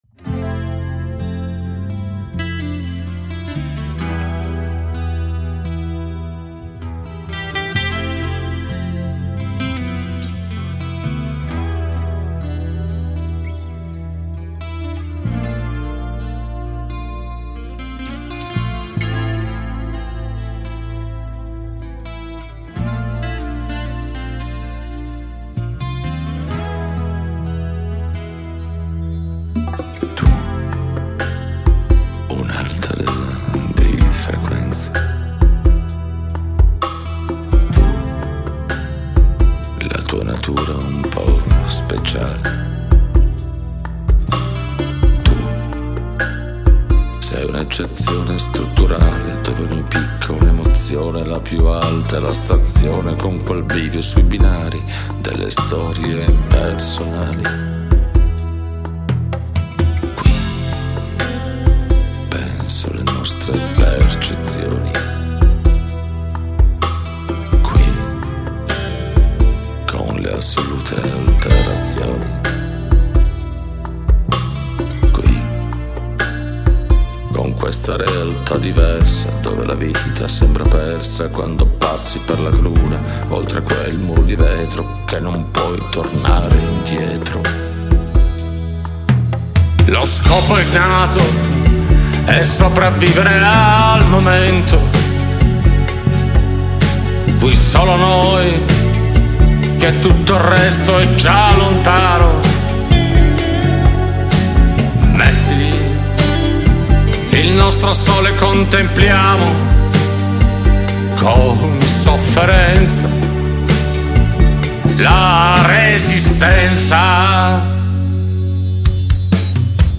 una band acustica
Lead Vocal
Guitars
Percussions
Bass